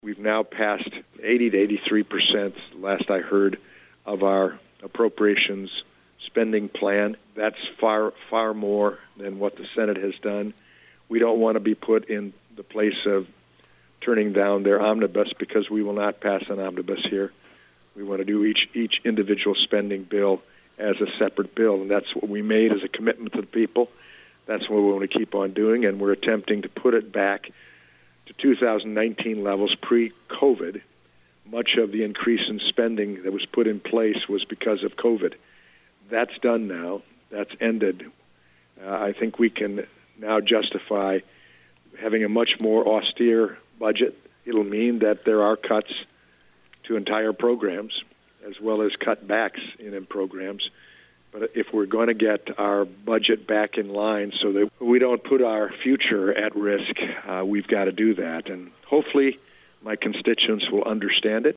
Congressman Walberg continued, talking about the status of the House Appropriations Spending Plan…